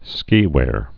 (skēwâr)